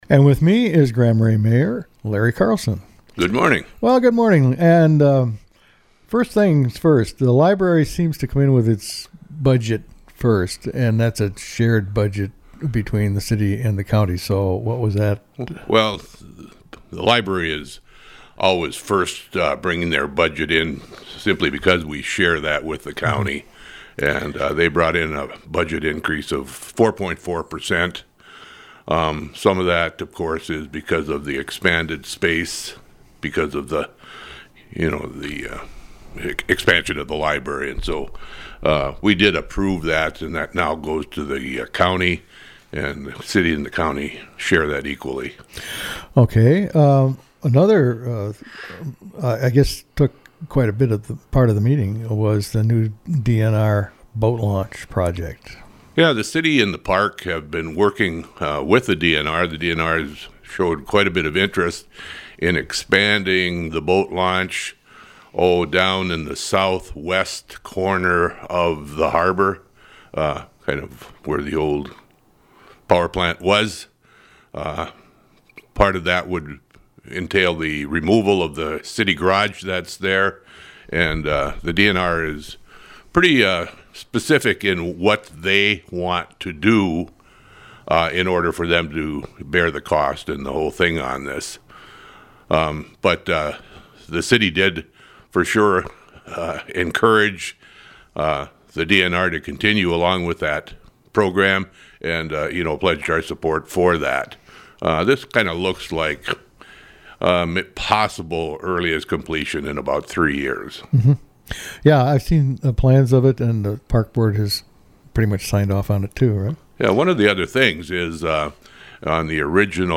To listen to the full interview including other topics besides the pool, click on the 9.63 MB audio file.